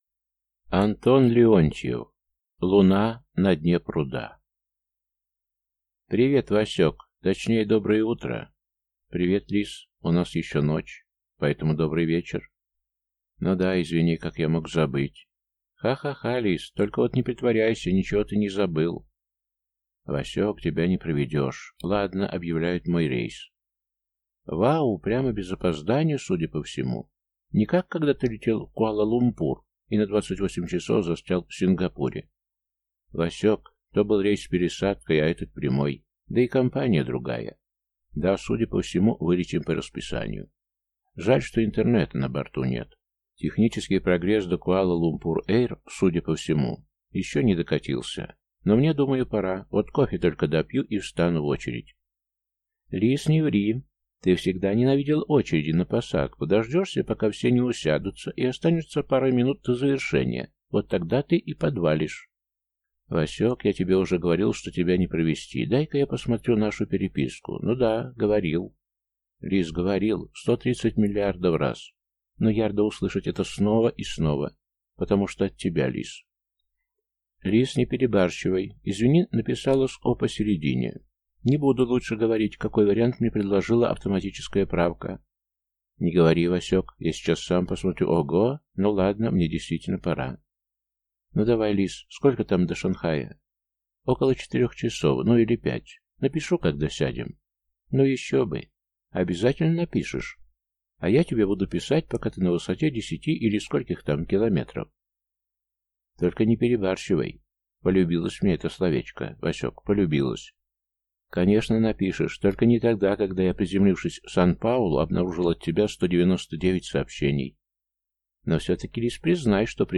Аудиокнига Луна на дне пруда | Библиотека аудиокниг